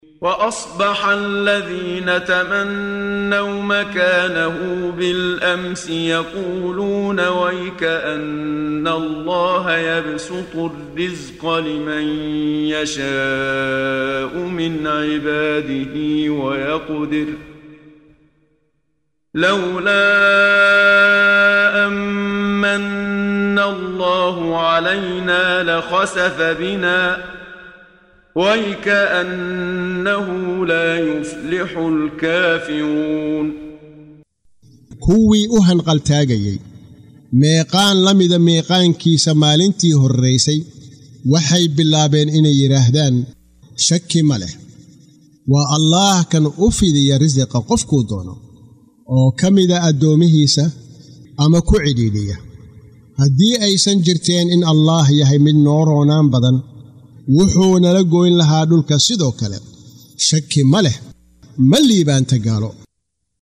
Waa Akhrin Codeed Af Soomaali ah ee Macaanida Suuradda Al-Qasas ( Qisooyinka ) oo u kala Qaybsan Aayado ahaan ayna la Socoto Akhrinta Qaariga Sheekh Muxammad Siddiiq Al-Manshaawi.